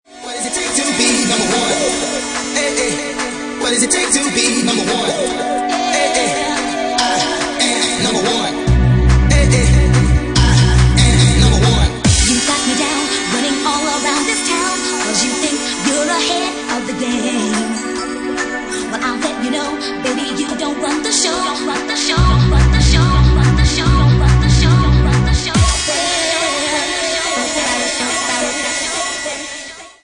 Bassline House